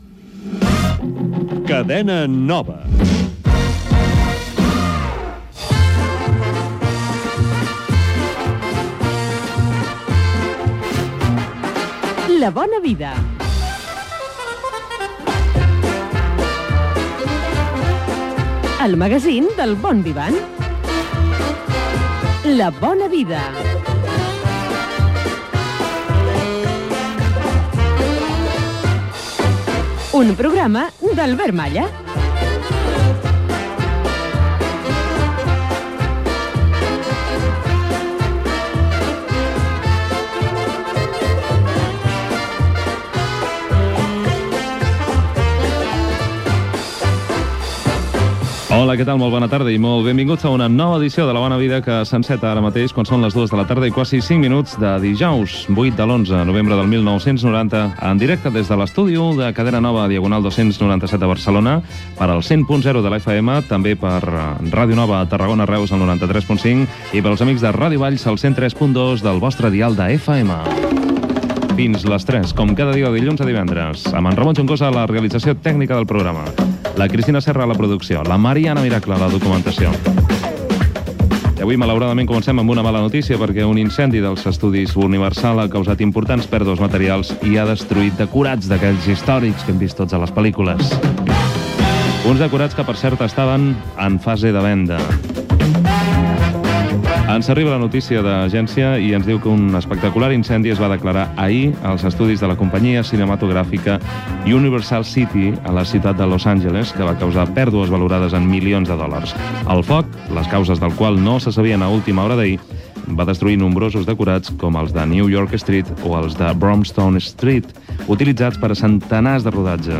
Indicatiu de la cadena. Careta del programa. Equip del programa. Incendi als estudis Universal d'EE.UU. Actuació al Palau de la Música. Sumari de continguts.
FM
Magazín dedicat a l'oci, cuina, espectacles, etc.